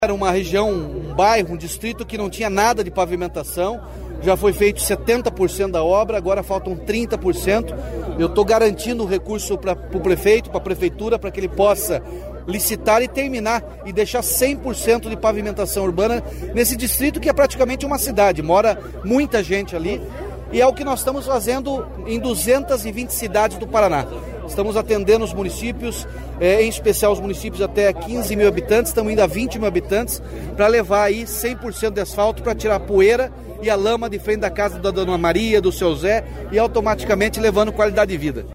Sonora do governador Ratinho Junior sobre a inauguração da Estrada Amitec e das obras de pavimentação do distrito de Jacaratiá, em Goioerê